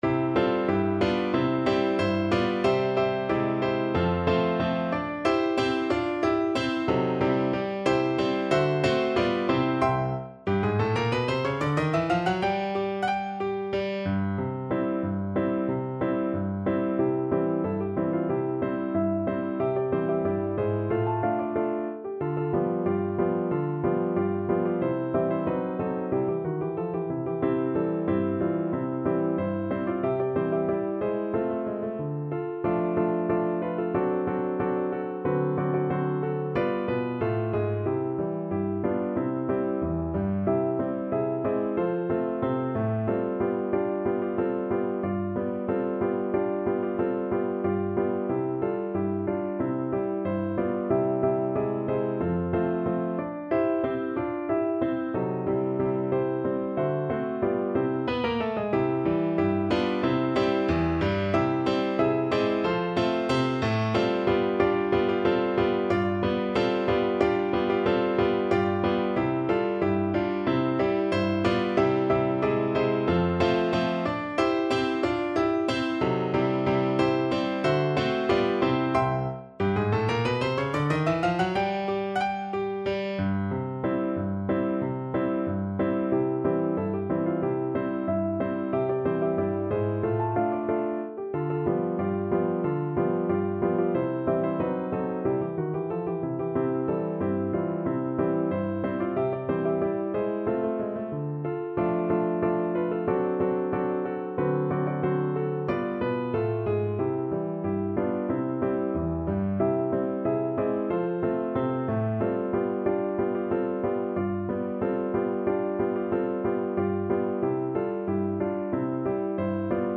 2/4 (View more 2/4 Music)
Allegretto =92
Traditional (View more Traditional Voice Music)